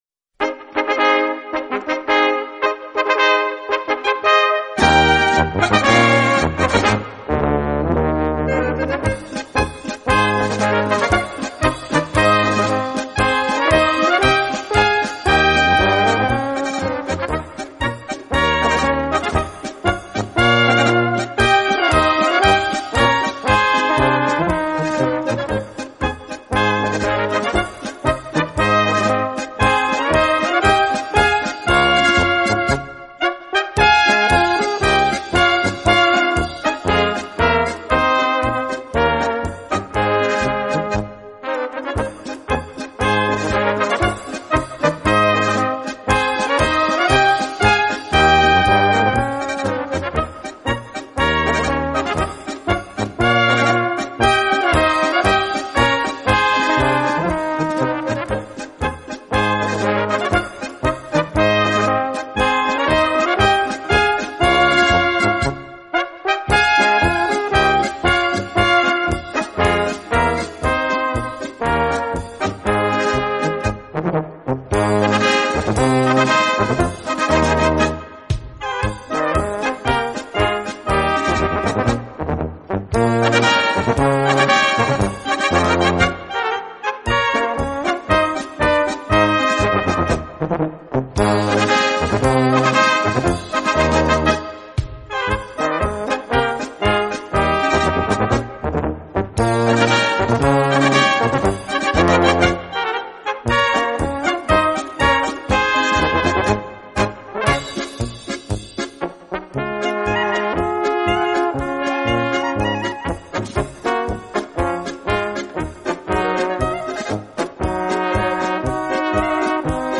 Gattung: für kleine Besetzung
Besetzung: Kleine Blasmusik-Besetzung